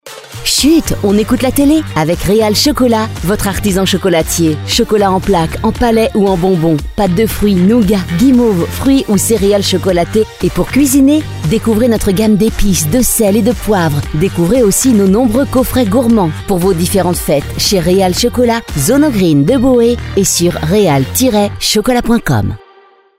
• voici le spot de notre partenaire pour le magasin de Agen Boé